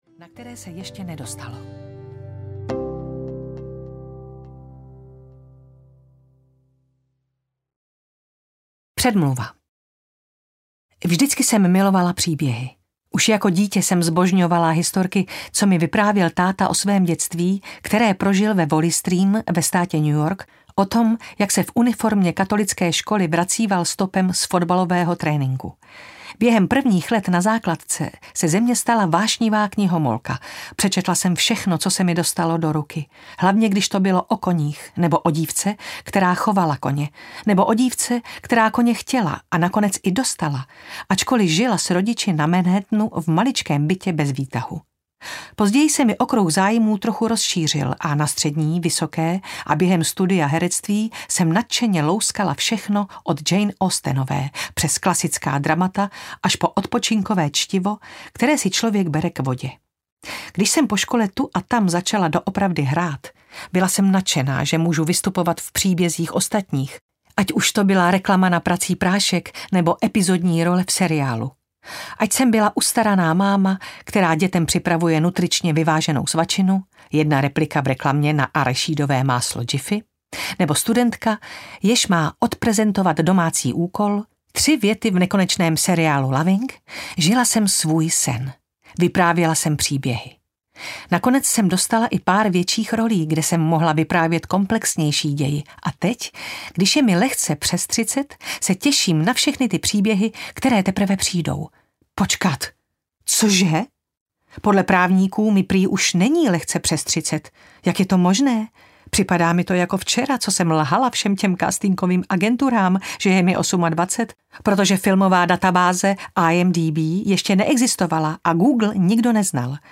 Už jsem ti to říkala? audiokniha
Ukázka z knihy